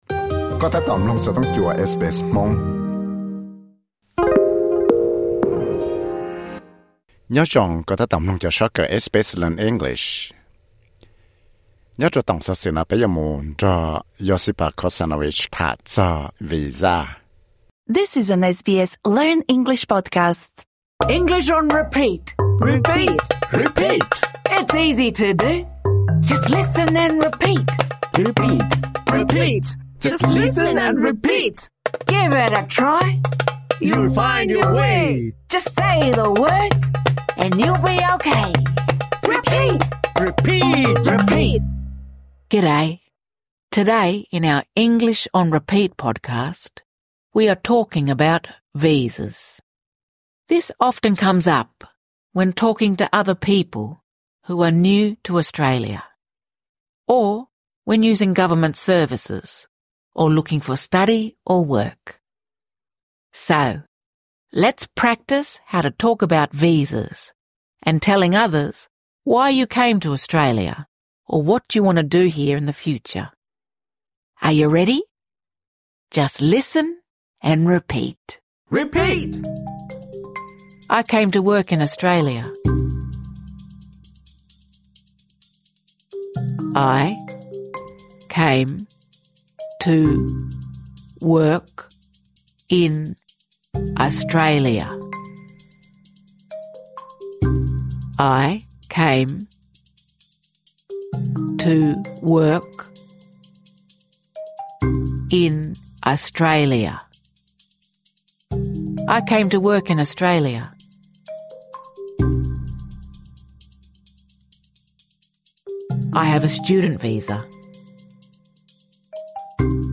Mloog tau cov sob kawm 'English on Repeat,' uas yog ib co sob kawm kaw ua suab pab peb kom paub tham lus.